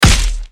Punch9.wav